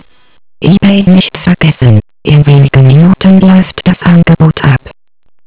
Standardmäßig sagt Ihnen eine weibliche Stimme: